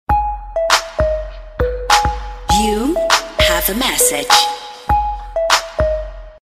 Рингтоны » На SMS